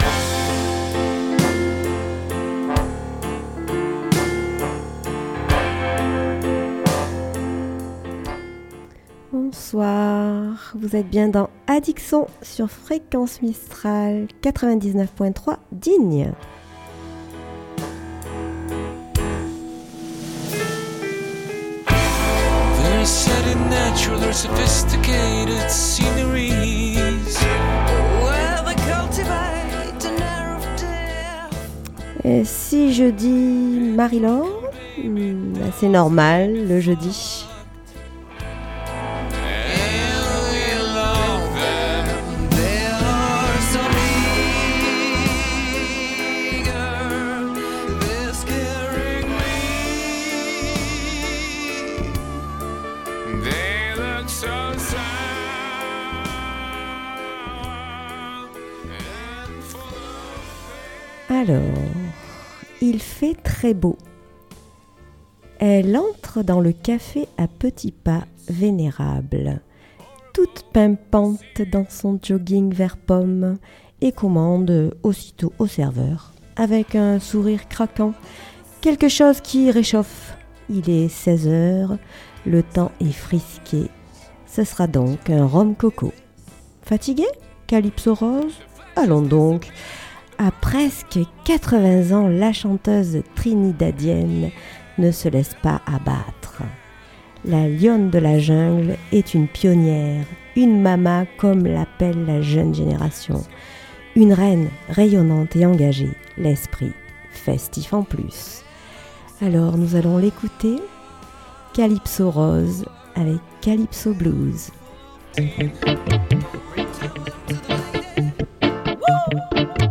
Addic Son - Emission musicale du 24 janvier 2019
chanson française mais aussi, du blues, du rock, du reggae, du rap, jazz, etc.